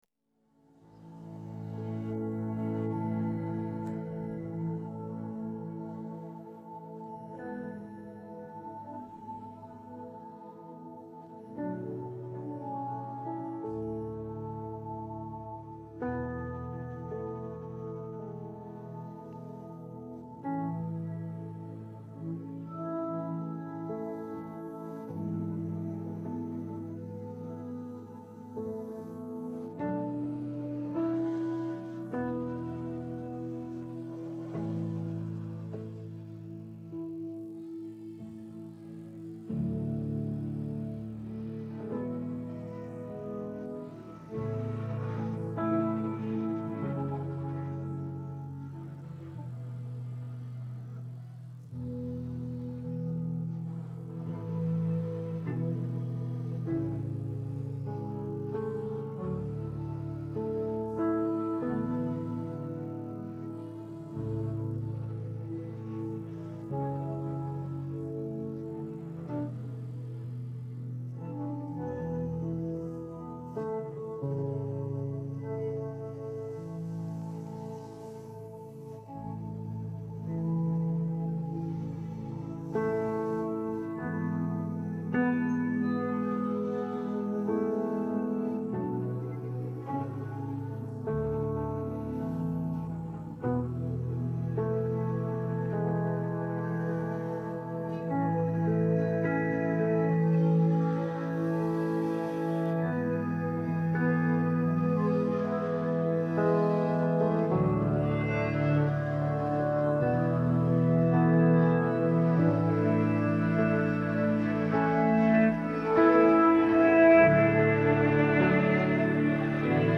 piano track